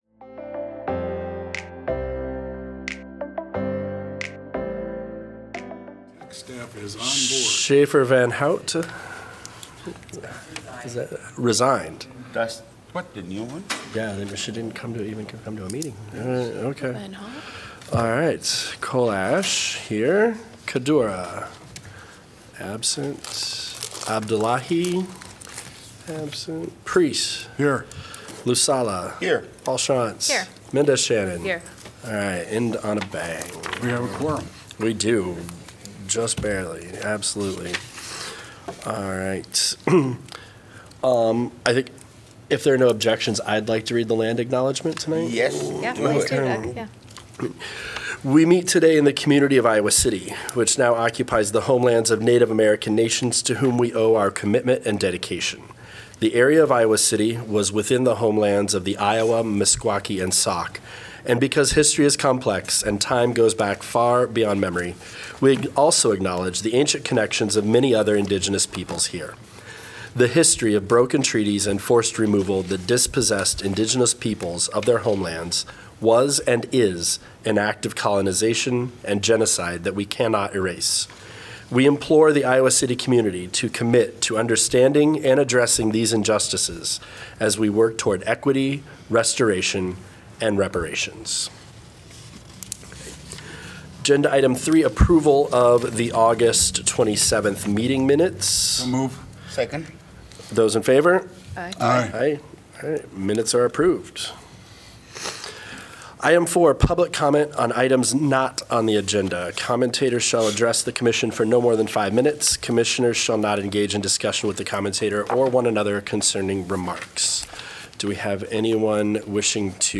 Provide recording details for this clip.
Regular monthly meeting of the Human Rights Commission, rescheduled from September 24.